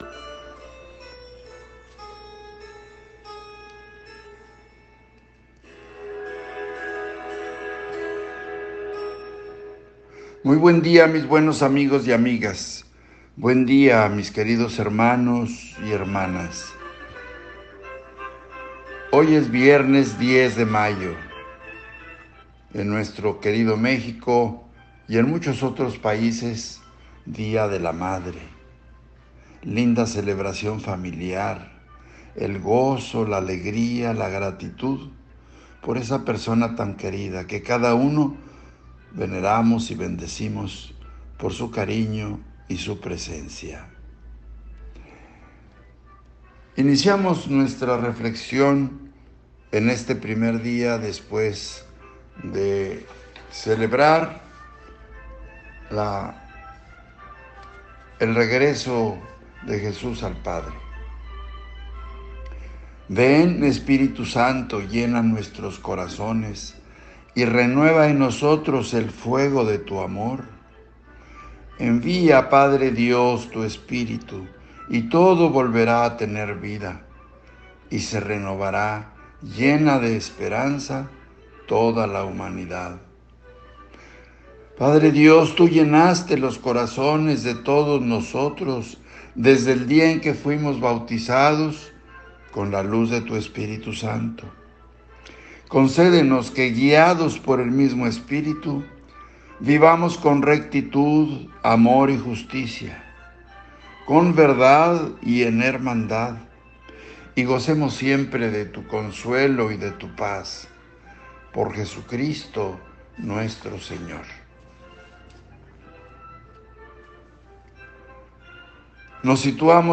Reflexión del Evangelio